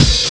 34 OP HAT.wav